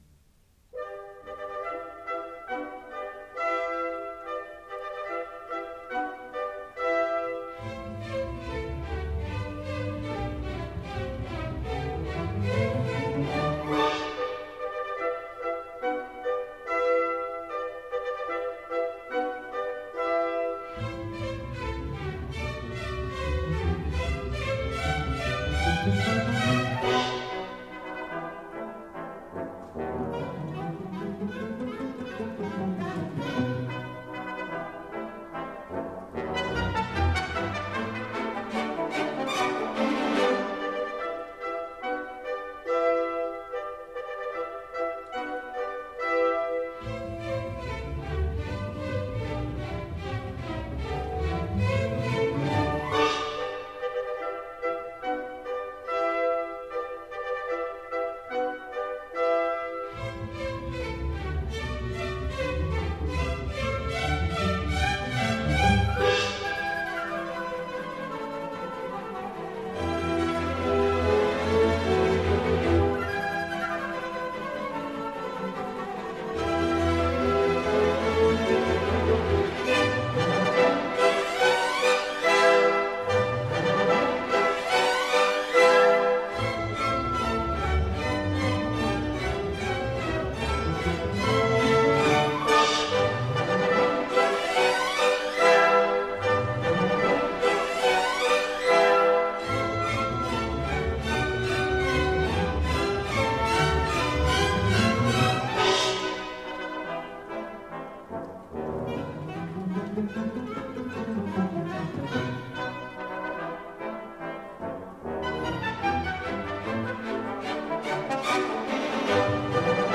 Ballet